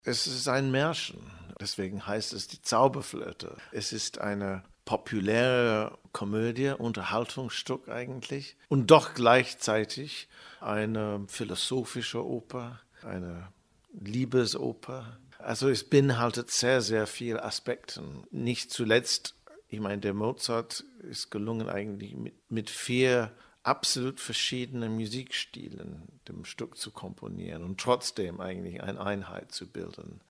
O-Töne vom Pressetag 2013 - Newsbeitrag
oton_pressetag2013_news.mp3